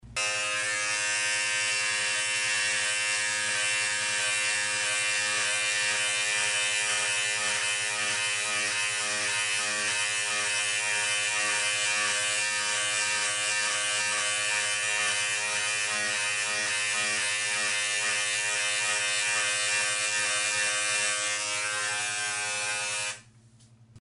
Звуки триммера
Триммером ровняют усы